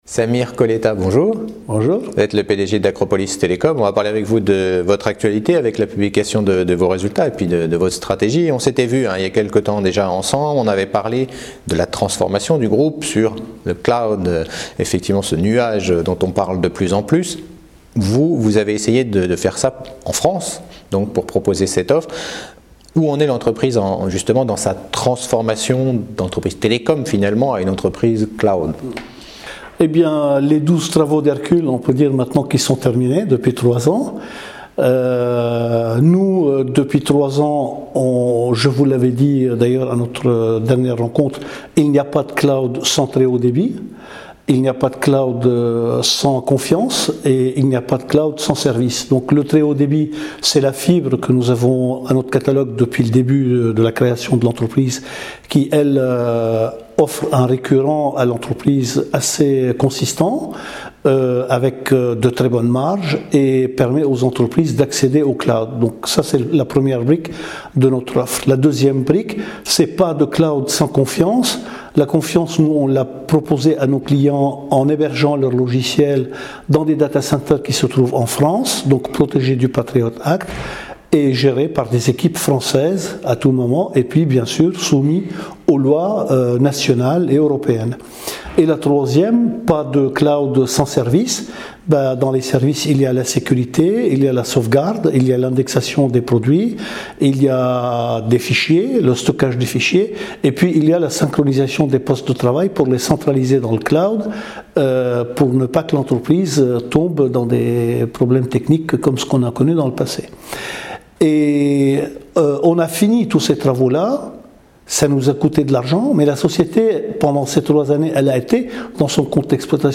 Cet interview a été tournée au Club Confair